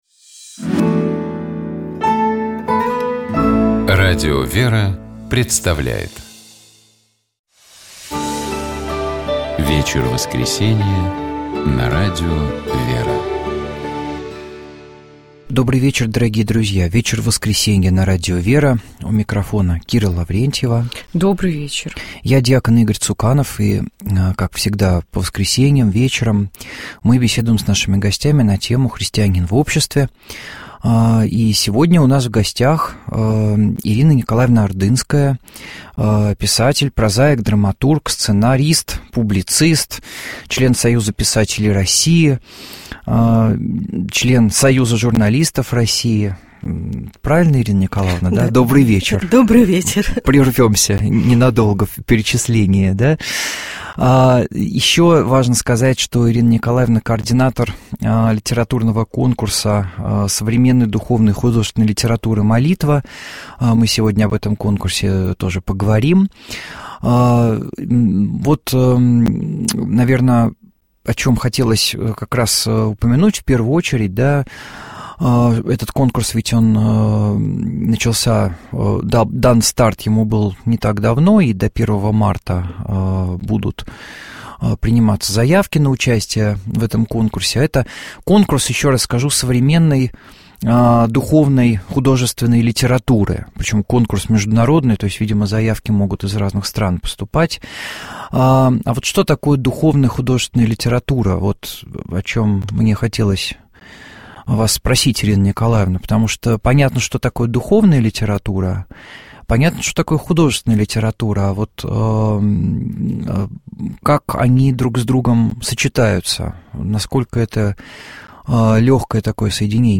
Комментирует священник